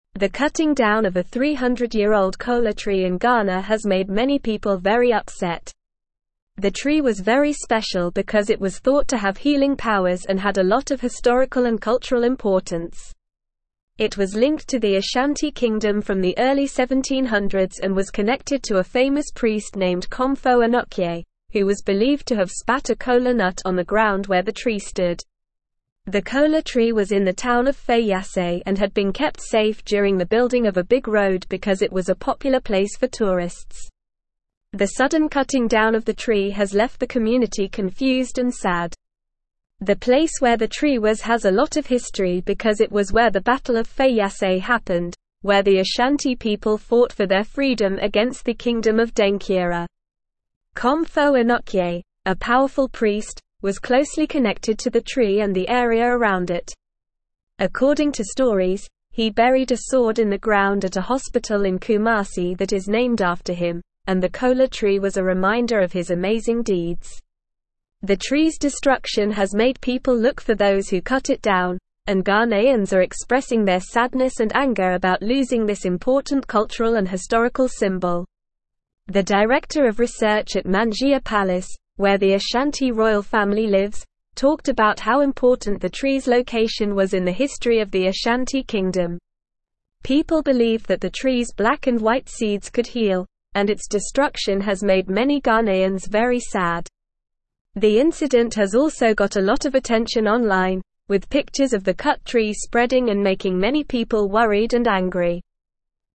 Normal
English-Newsroom-Upper-Intermediate-NORMAL-Reading-Manhunt-in-Ghana-for-Culprits-Behind-Ancient-Trees-Destruction.mp3